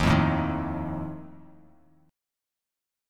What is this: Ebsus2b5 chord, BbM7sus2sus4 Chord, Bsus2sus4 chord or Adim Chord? Ebsus2b5 chord